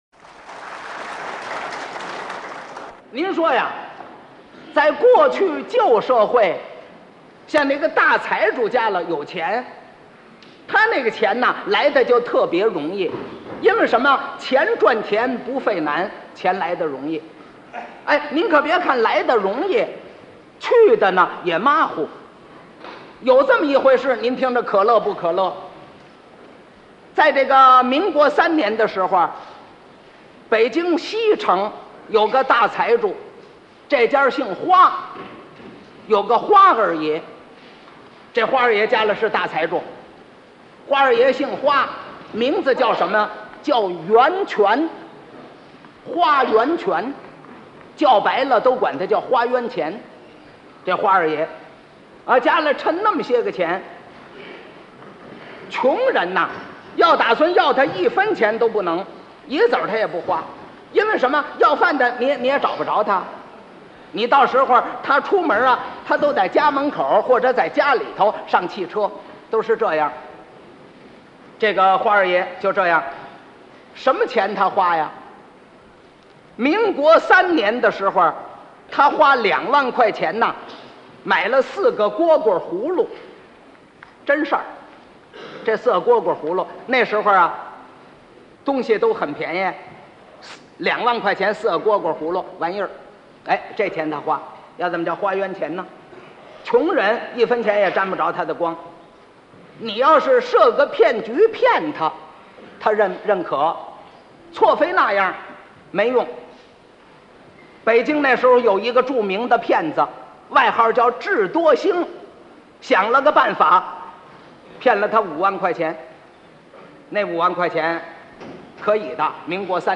单口相声在表演上比对口、群口相声更为简便，只要一个人说表就够了。
刘宝瑞的艺术特点是“评”“叙”细密，幽默风趣，表情微妙，朴实无华。
为了挖掘整理优秀的文化遗产，丰富人民的文化生活，满足广大相声爱好者的珍藏和欣赏要求，我们通过先进的科技手段将刘宝瑞先生的珍藏录音进行了加工。